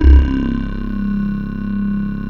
M12-LOW C2-L.wav